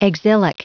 Prononciation du mot exilic en anglais (fichier audio)
Prononciation du mot : exilic